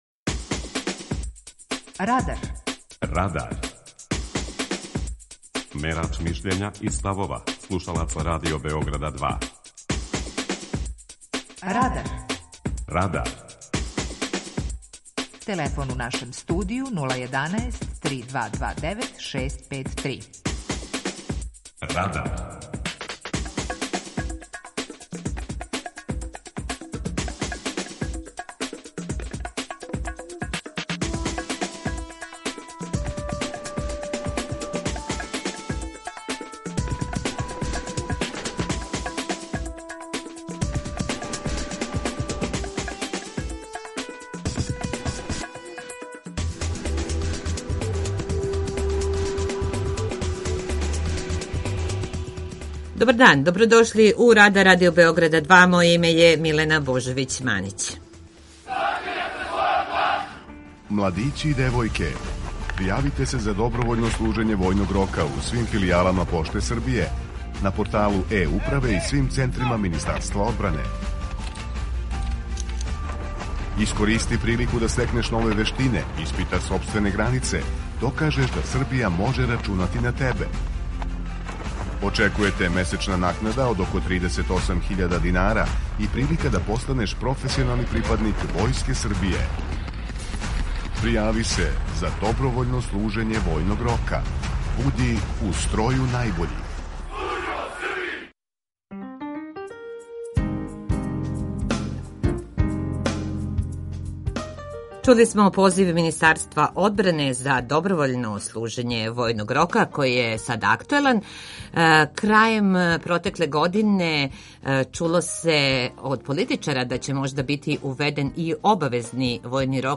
Питање Радара је: Треба ли вратити обавезно служење војног рока? преузми : 18.83 MB Радар Autor: Група аутора У емисији „Радар", гости и слушаоци разговарају о актуелним темама из друштвеног и културног живота.